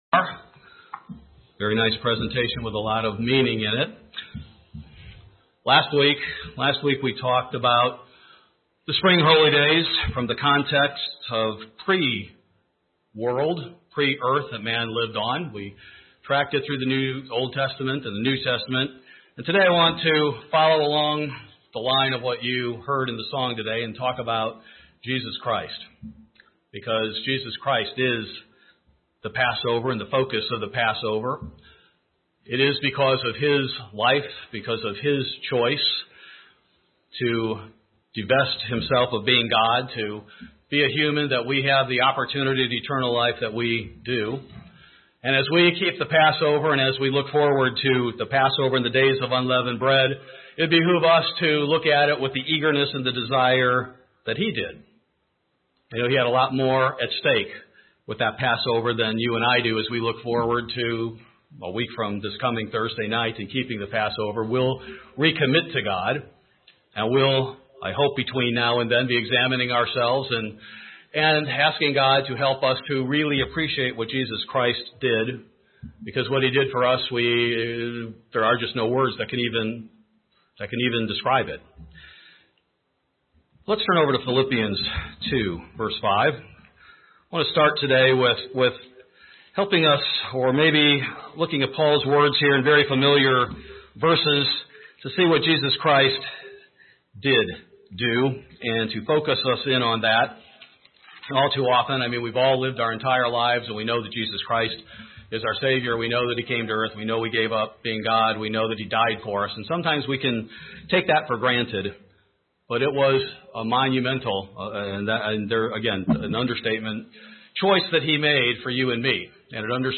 Sermon From the Cross